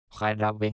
Hanno il suono di una "h" aspirata la consonante -g-, usata nei gruppi -ge, gi-, e la lettera -j- sempre.